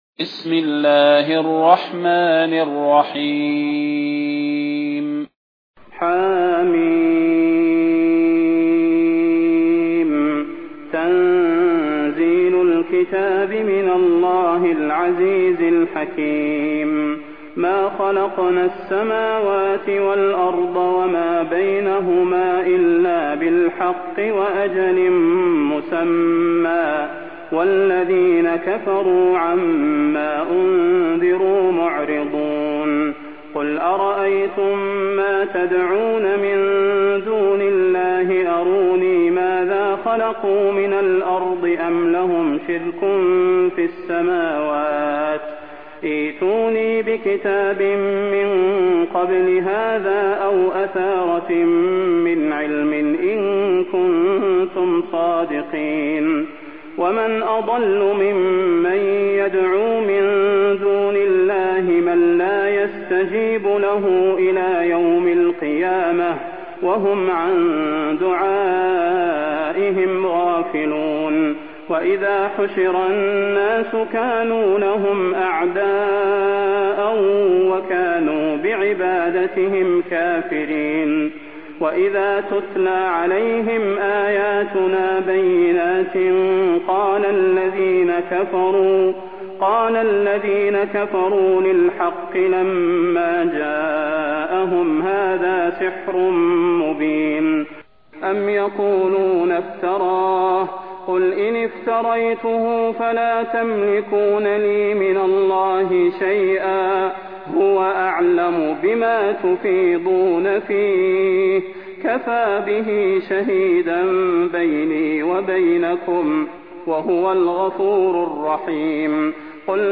المكان: المسجد النبوي الشيخ: فضيلة الشيخ د. صلاح بن محمد البدير فضيلة الشيخ د. صلاح بن محمد البدير الأحقاف